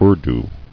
[Ur·du]